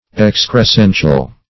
Search Result for " excrescential" : The Collaborative International Dictionary of English v.0.48: Excrescential \Ex`cres*cen"tial\a. Pertaining to, or resembling, an excrescence.